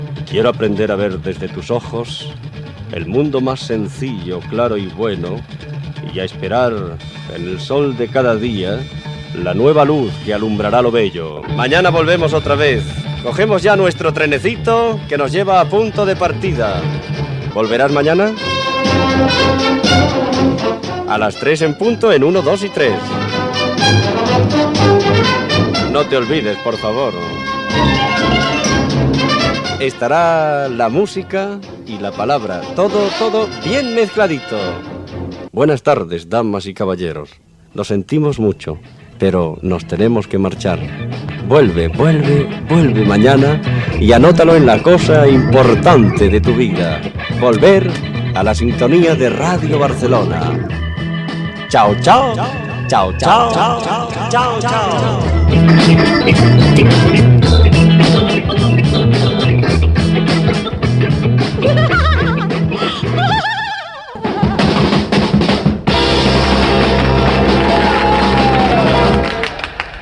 Comiat del programa
Entreteniment